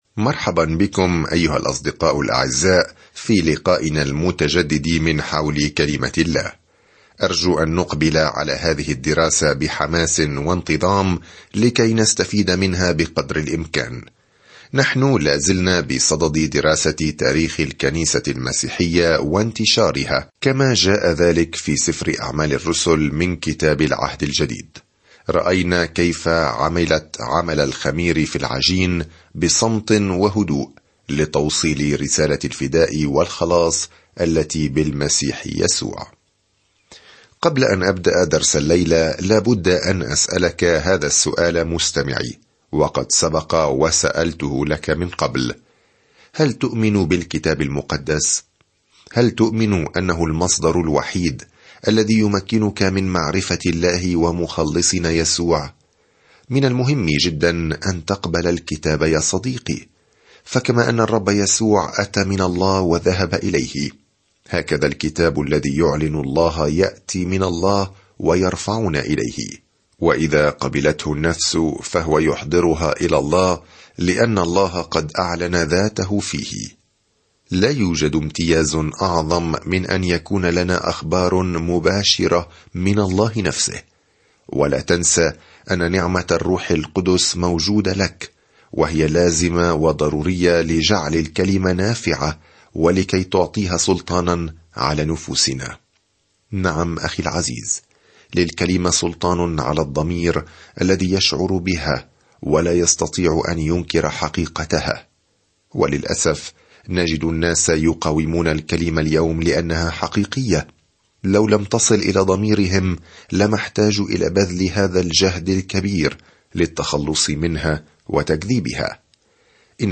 الكلمة أَعْمَالُ ٱلرُّسُلِ 9:18-28 أَعْمَالُ ٱلرُّسُلِ 1:19-7 يوم 22 ابدأ هذه الخطة يوم 24 عن هذه الخطة يبدأ عمل يسوع في الأناجيل ويستمر الآن من خلال روحه، حيث تُزرع الكنيسة وتنمو في جميع أنحاء العالم. سافر يوميًا عبر سفر أعمال الرسل وأنت تستمع إلى الدراسة الصوتية وتقرأ آيات مختارة من كلمة الله.